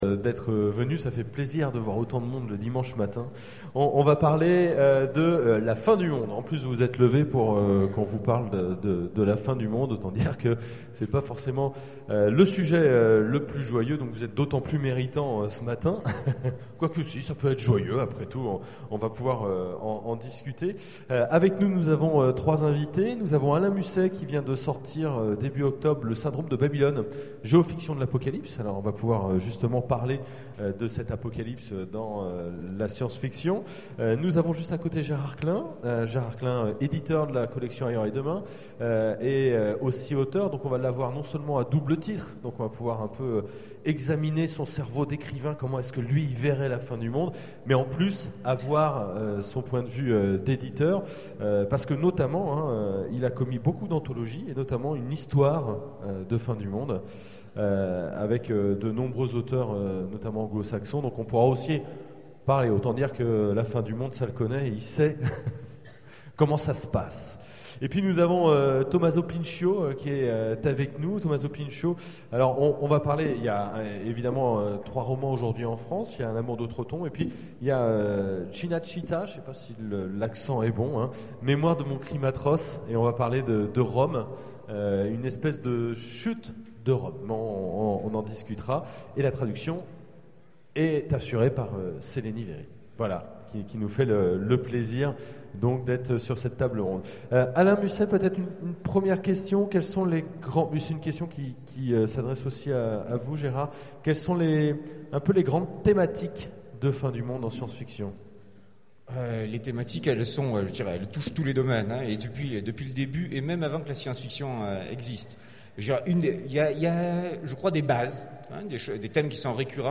Utopiales 12 : Conférence La fin du monde pour le mois prochain ?